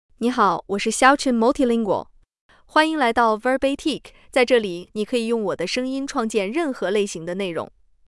Xiaochen MultilingualFemale Chinese AI voice
Xiaochen Multilingual is a female AI voice for Chinese (Mandarin, Simplified).
Voice sample
Listen to Xiaochen Multilingual's female Chinese voice.
Female